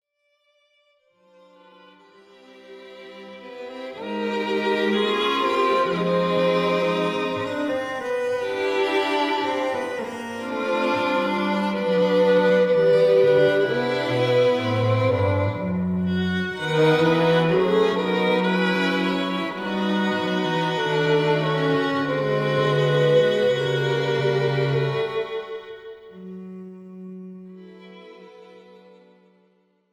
This is an instrumental backing track cover.
• Key – C
• Without Backing Vocals
• No Fade